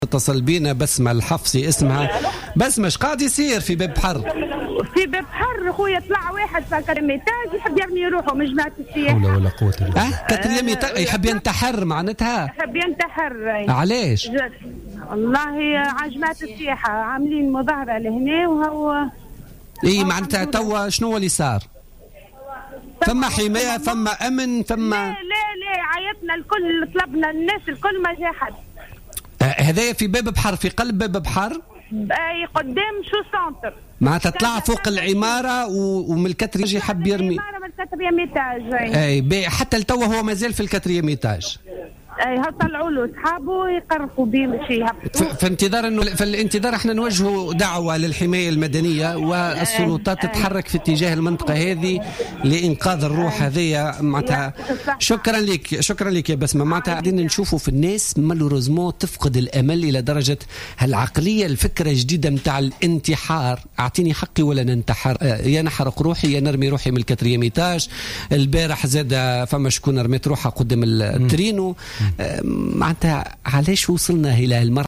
وقد أكدت مواطنة كانت شاهد عيان على ماحدث أن المواطنين تجمعوا بمكان الحادث محاولين اقناعه بالعدول عما كان ينوي فعله حيث نجحوا في النهاية بمنعه من إلقاء نفسه وانزاله من هناك.